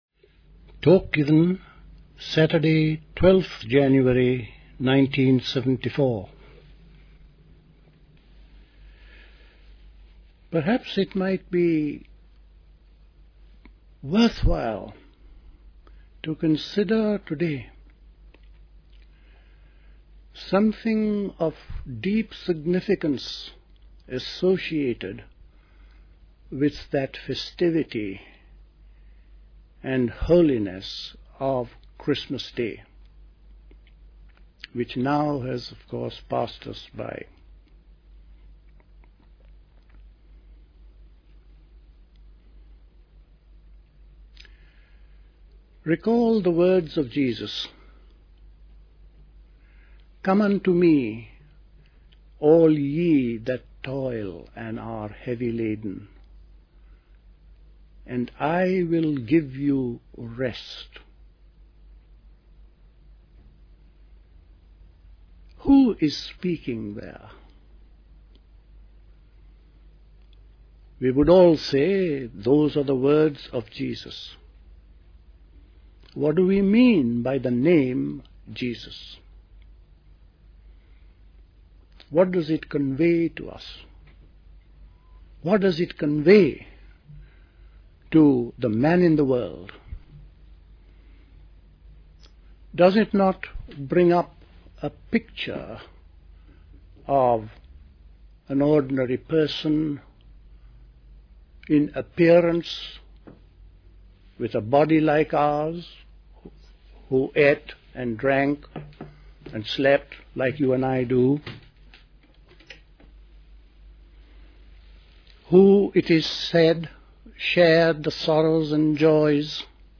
A talk
at Dilkusha, Forest Hill, London on 12th January 1974